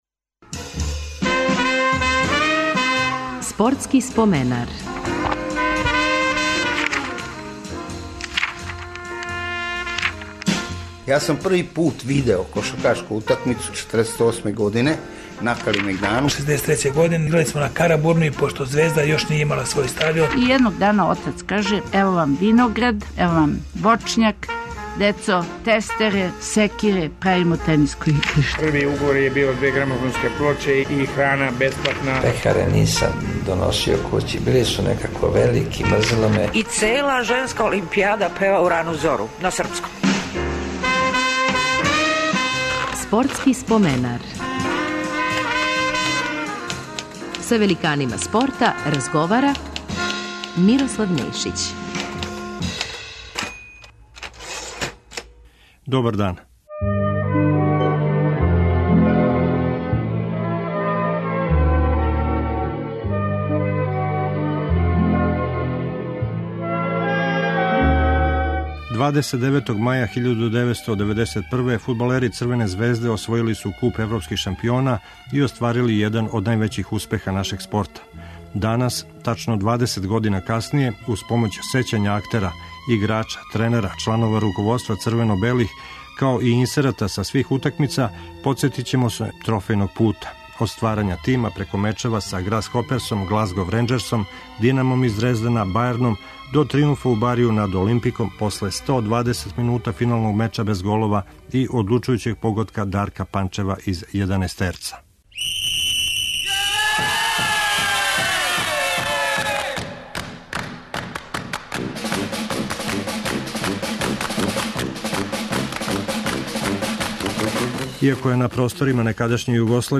Уз помоћ сећања Драгана Џајића, Стевана Стојановића, Љупка Петровића и Миодрага Белодедића снимљених специјално за ову емисију, као и изјава Дејана Савићевића, Дарка Панчева, Роберта Просинечког... из времена похода црвено-белих ка европском врху, инсерата из преноса свих утакмица, подсетићемо вас на победе против Грасхоперса, Глазгов ренџерса, Динама из Дрездена, Бајерна и Олимпика.